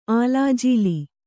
♪ ālājīli